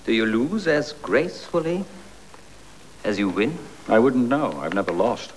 Here are a few sound bites from the movie: